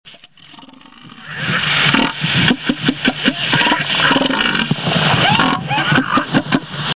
Iles Kerguelen - éléphants de mer
Eléphant de mer, maître mâle. Baie de l'Aurore Australe.